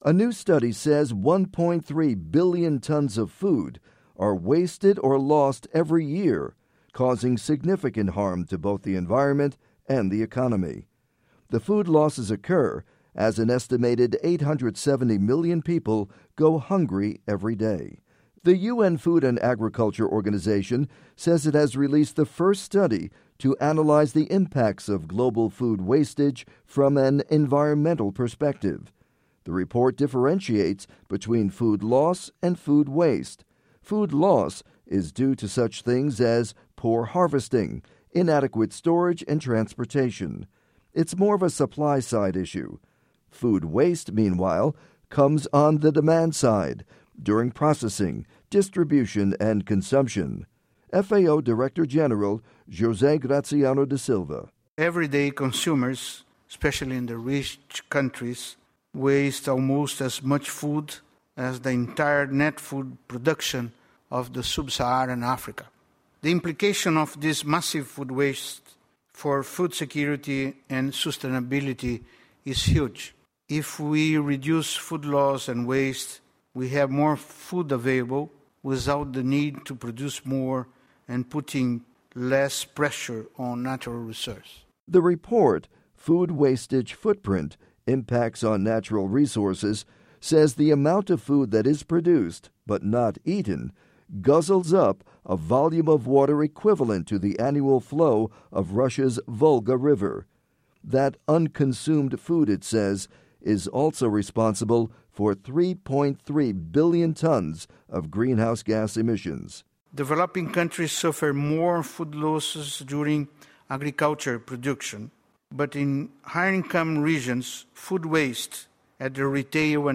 report on food waste